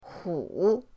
hu3.mp3